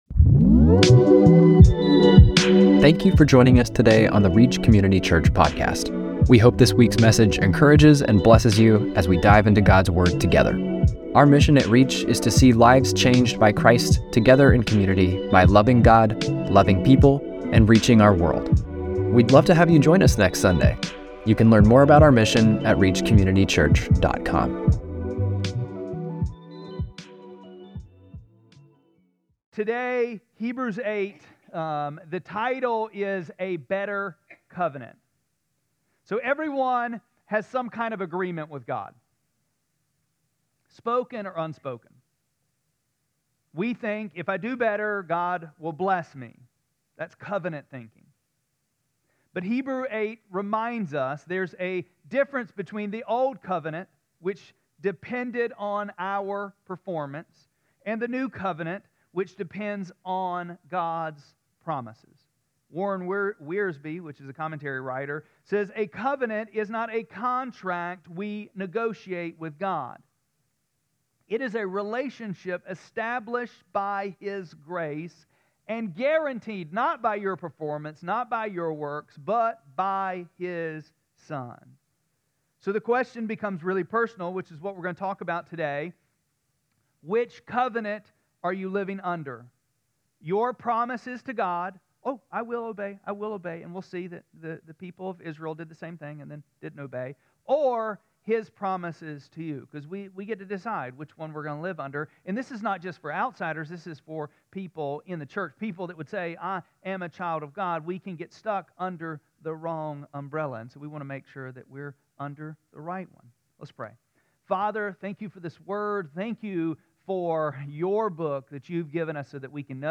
11-9-25-Sermon.mp3